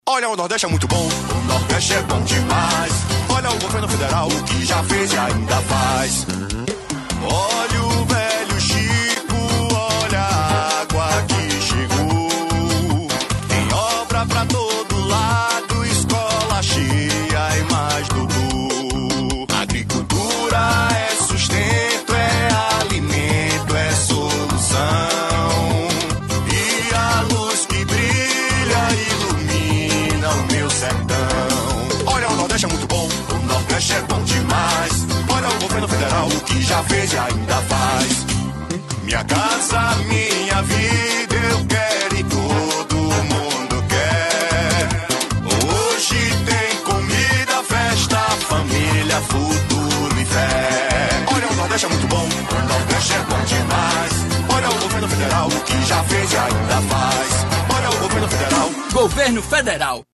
Spots e Jingles produzidos pela rede gov e por órgãos do governo federal.